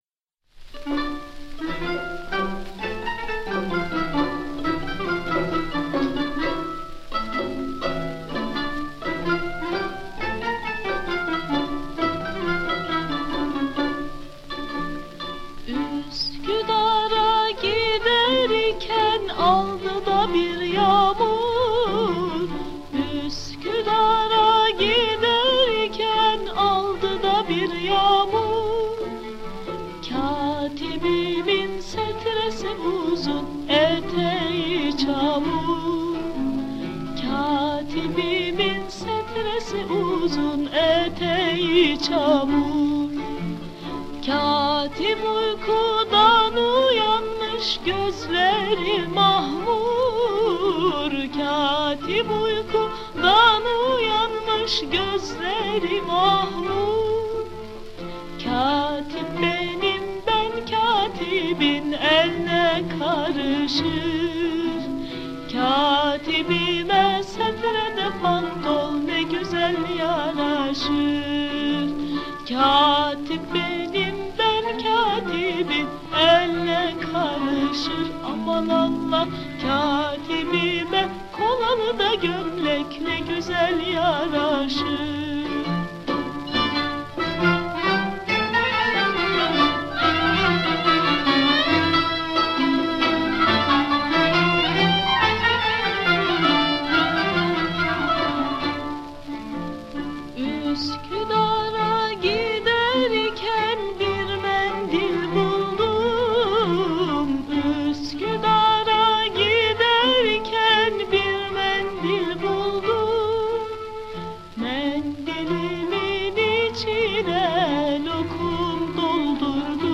Sound sample of Turkish classical piece Katibim (Uskudar'a Gider iken)